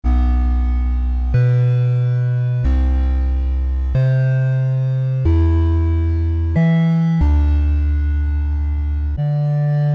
Category: Electro RIngtones